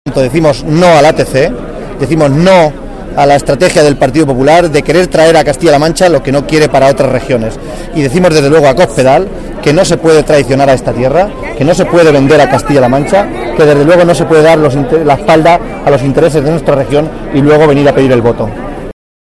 señaló durante la manifestación que tuvo lugar en Tarancón que “decimos no al ATC en Castilla-La Mancha y decimos no a la estrategia del PP de querer traer a nuestra región lo que no quiere para otras”